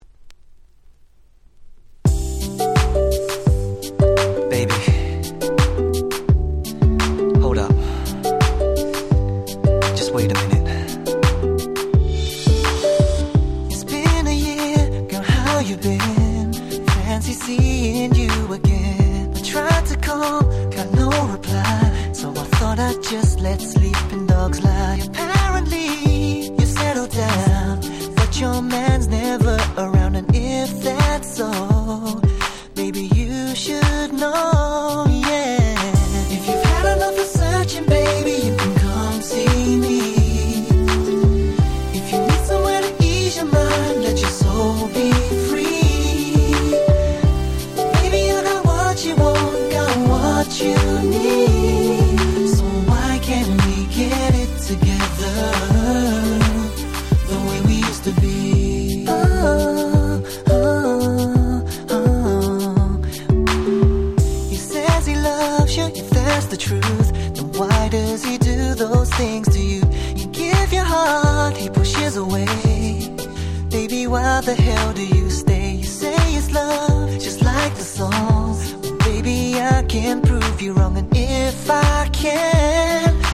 08' 美メロR&B♩
思い切り一般受けしそうな美メロ&Smoothのキラキラな2曲！